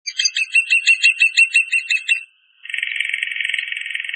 En cliquant ici vous entendrez le chant du Pic épeichette.
Le Pic épeichette